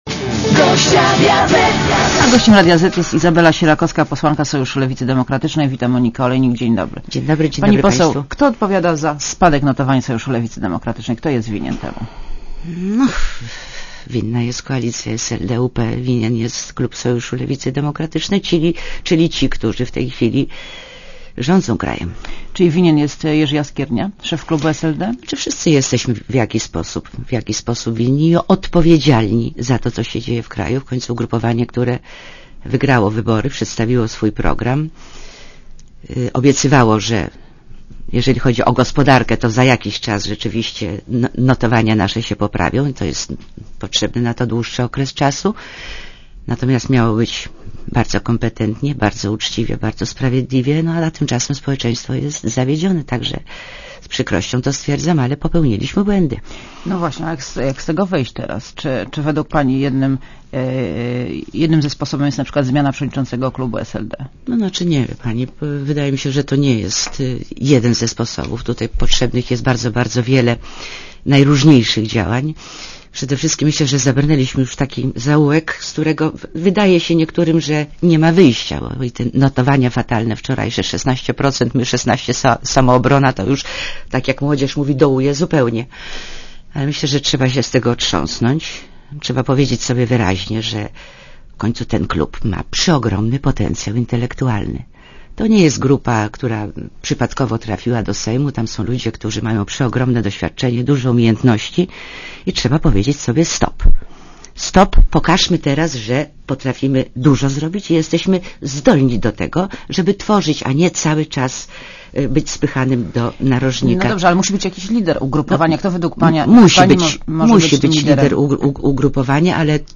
Izabela Sierakowska w Radiu Zet (RadioZet)